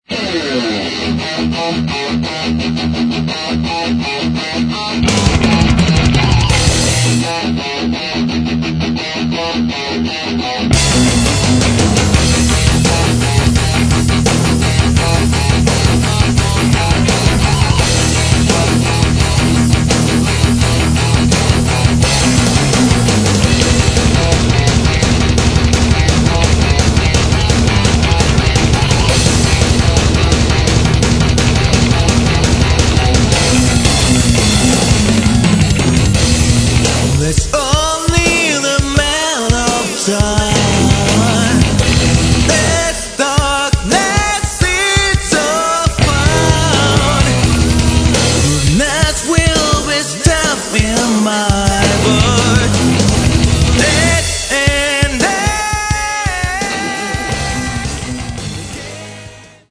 Metal
Неоклассический шедевр!